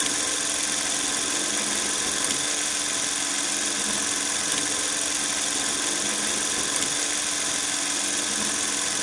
De Jur Electra 8mm电影摄像机 03
描述：电影通过8毫米电影摄影机滚动 记录在Tascam DR40上
标签： 电影 电影 摄像头 免费 电影 8毫米
声道立体声